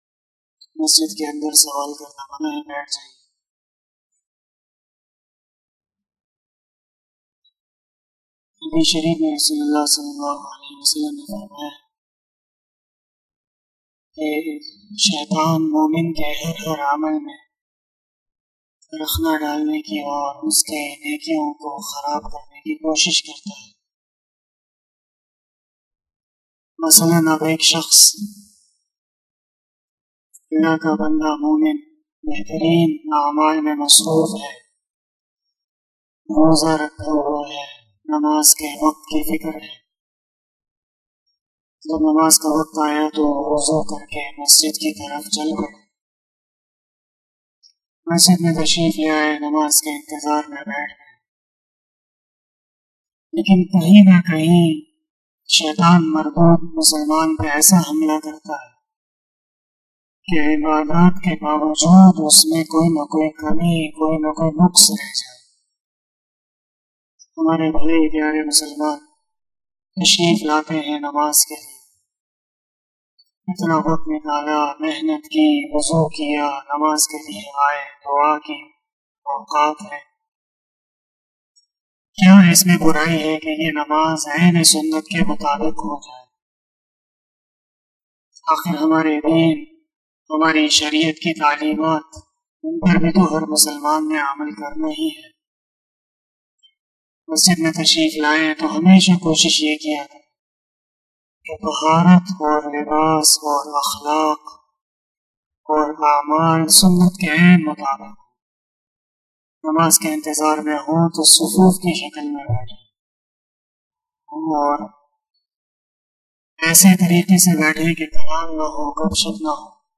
013 After Asar Namaz Bayan 21 April 2021 ( 08 Ramadan 1442HJ) Wednesday